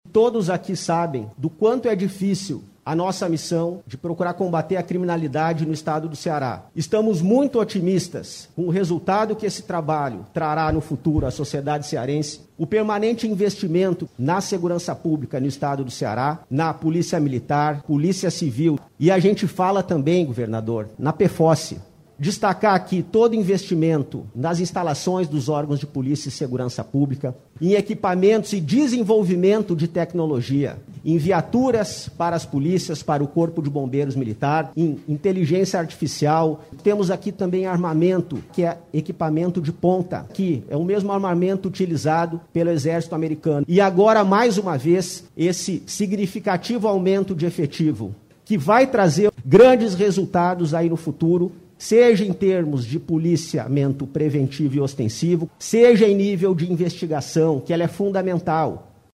Em transmissão ao vivo via redes sociais na manhã desta quarta-feira (18), o governador do Ceará, Camilo Santana anunciou novos concursos públicos para a área da Segurança Pública do Ceará, com um total de 3.128 vagas, sendo 2.200 para Polícia Militar, 500 para a Polícia Civil, 170 para a Perícia Forense (Pefoce), e também autorizou o chamamento dos 258 aprovados nos últimos concursos do Corpo de Bombeiros.
O secretário da Segurança Pública e Defesa Social, Sandro Caron, agradeceu os investimentos e explicou que o retorno vai ser percebido nos resultados dos trabalhos desenvolvidos pelas forças de segurança.